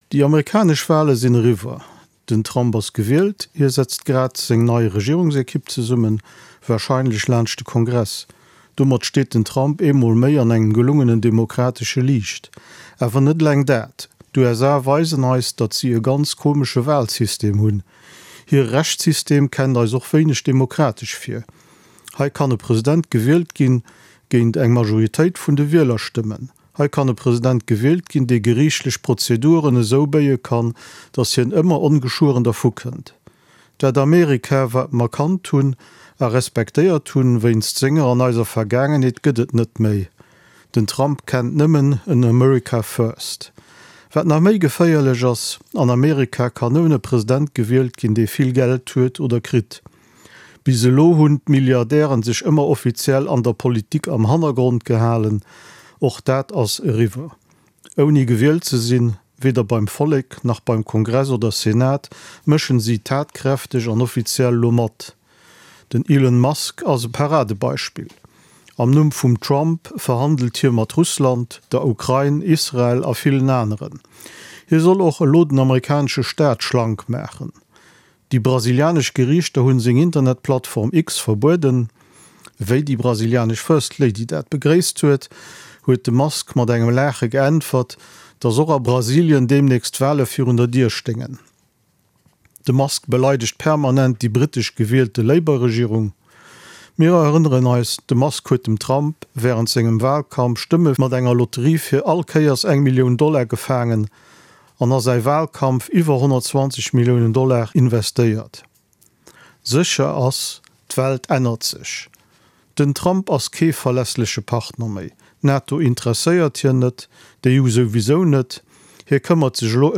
Commentaire Carte Blanche RTL Luxembourg RTL Radio Lëtzebuerg News Luxembourg RTL